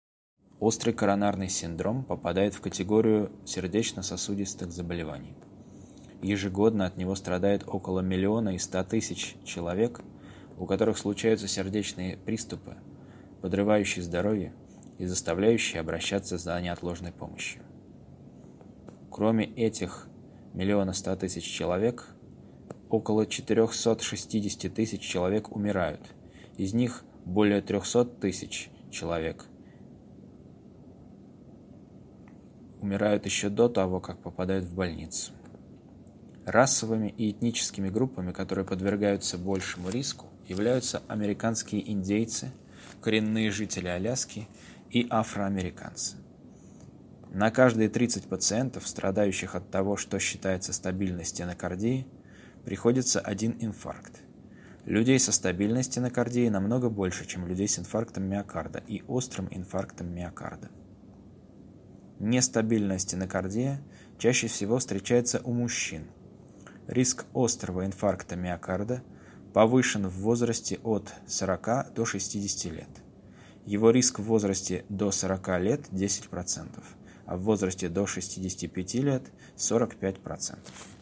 Перевод и озвучка на русском (медицина)